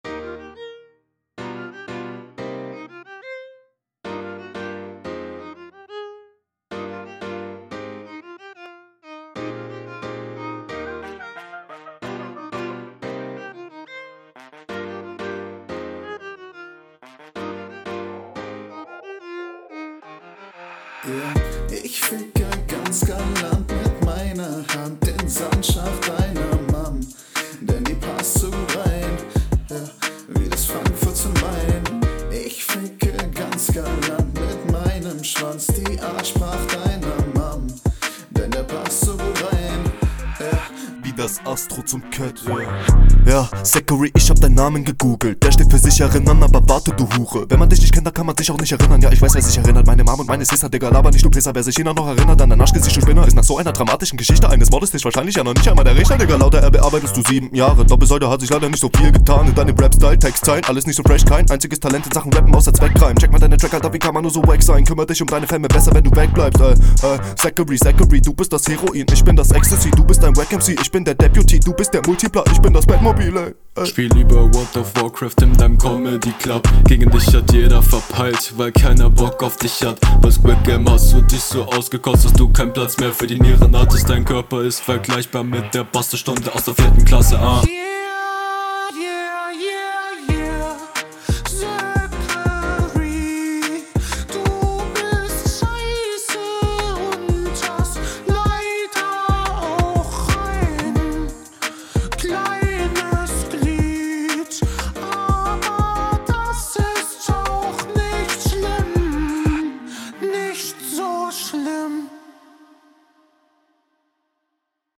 Die gesangs in und Outros fand ich echt nicht geil, sonst ist die Mische hier …